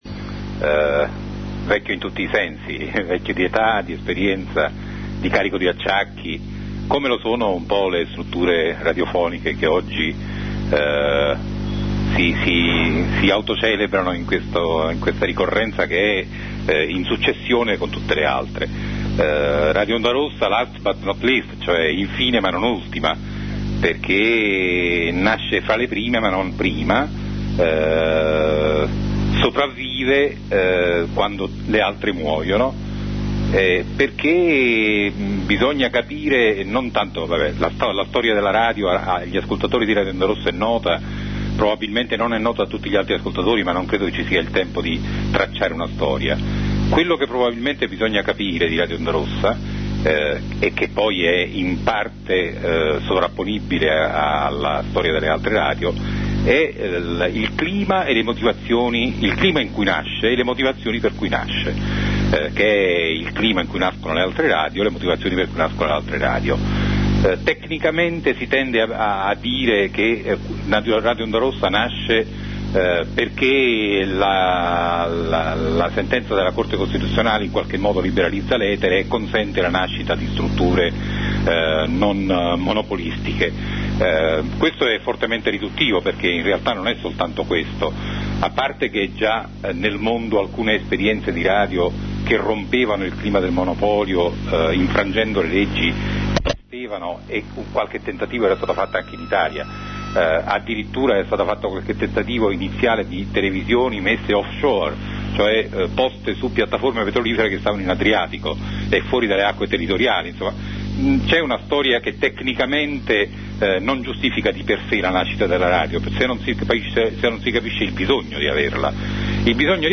da una trasmissione realizzata da Radio Gap sabato 10 maggio.
Intervista a Radio Onda Rossa